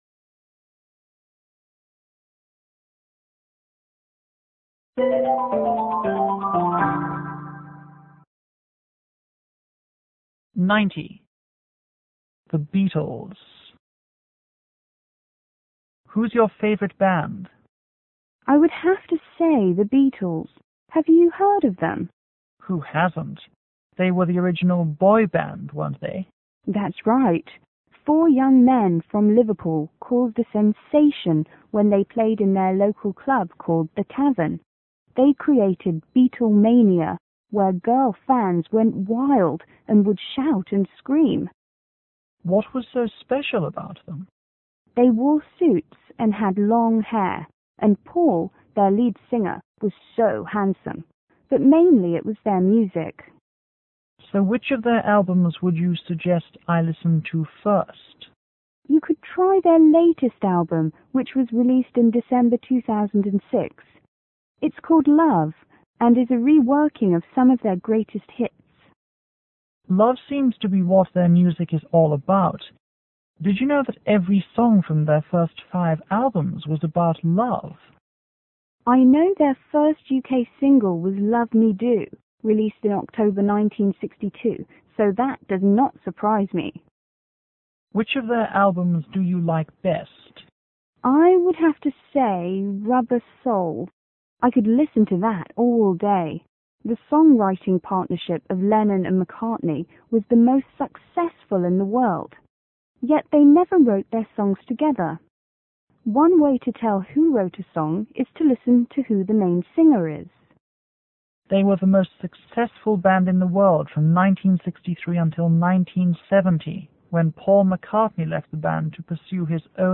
C: Chinese student           E: English teacher